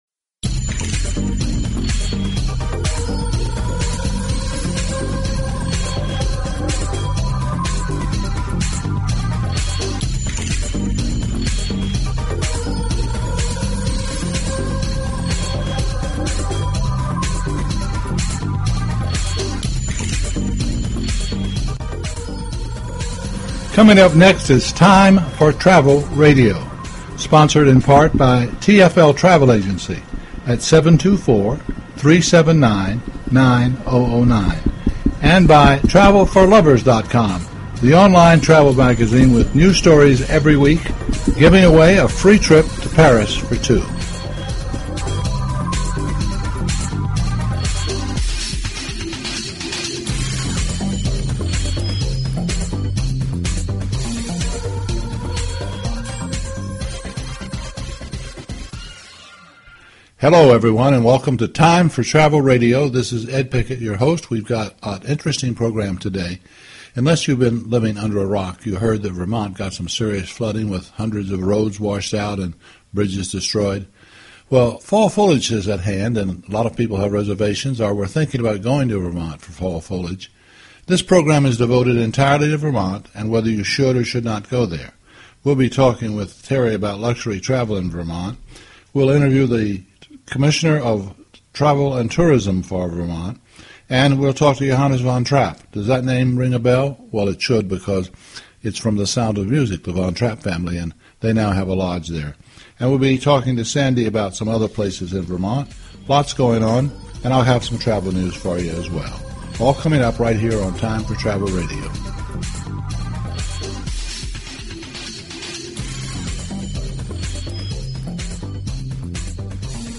Talk Show Episode, Audio Podcast, Time_for_Travel_Radio and Courtesy of BBS Radio on , show guests , about , categorized as